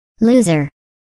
Звуки лузеров
На этой странице собраны забавные звуки лузеров – от провальных фраз до эпичных неудач.